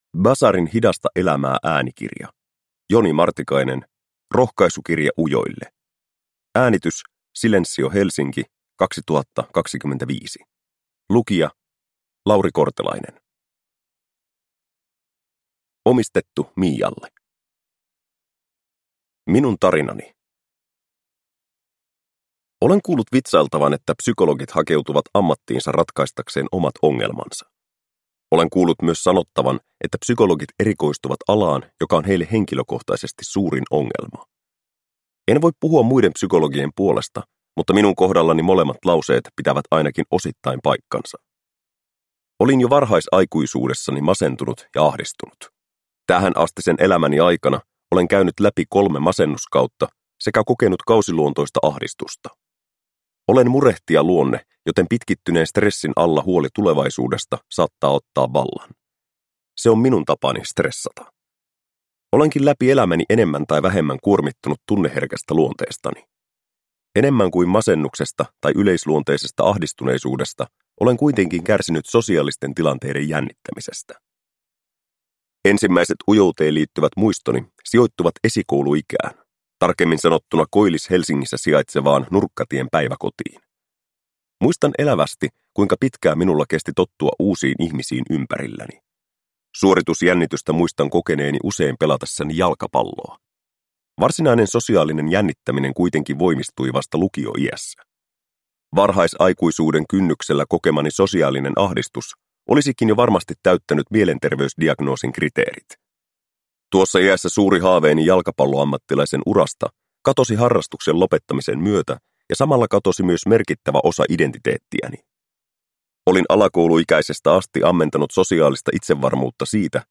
Rohkaisukirja ujoille – Ljudbok